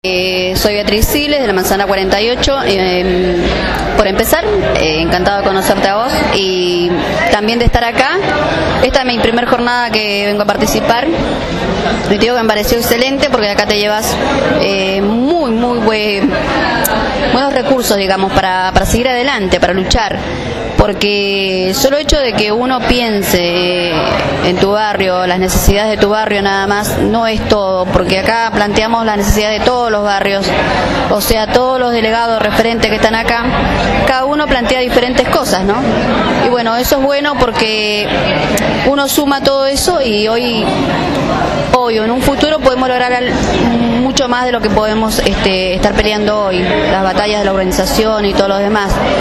El pasado sábado 12 de noviembre al mediodía se realizó la Jornada de Reflexión «Urbanización Participativa y Organización Popular» realizada en la Iglesia La Esperanza de la villa 1-11-14, Bajo Flores, Comuna 7.
Radio Gráfica estuvo presente y entrevistó al término de la Jornada a vecinos y referentes.